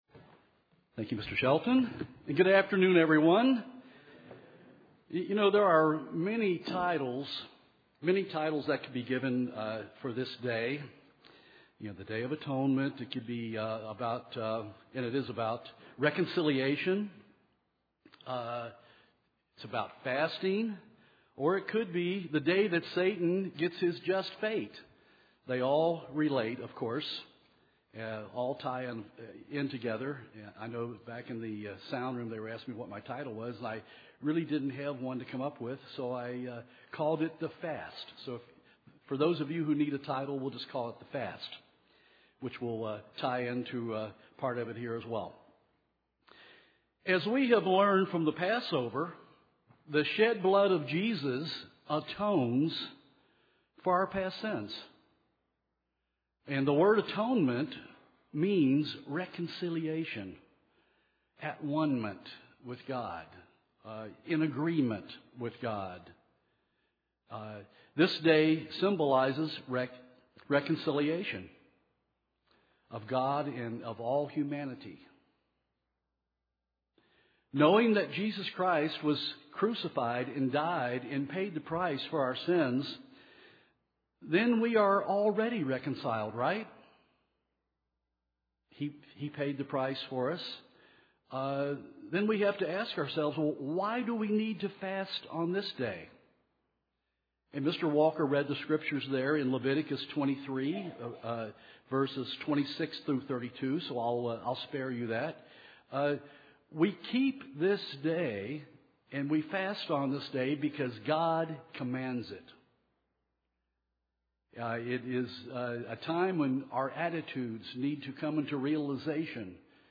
Given in Nashville, TN
Read more about the Day of Atonement UCG Sermon Studying the bible?